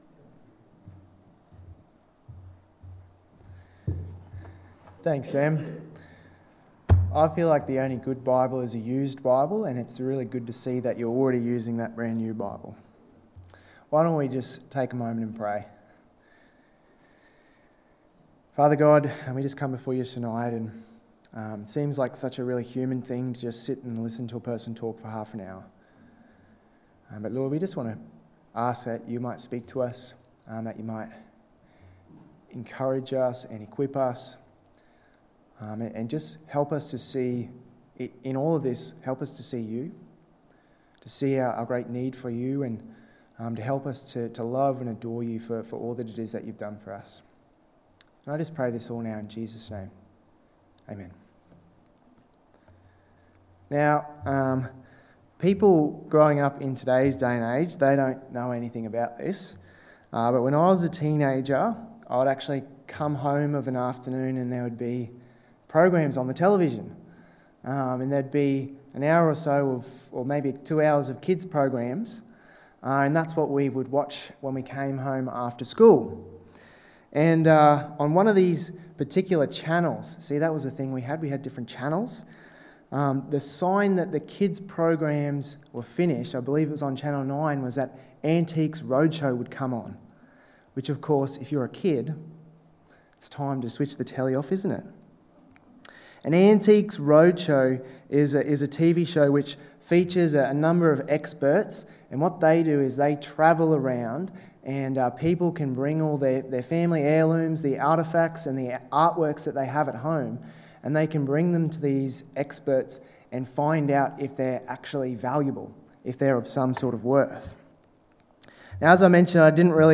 The Character of Authentic Christianity (James 1:19-27 Sermon) 25/06/23 Evening Service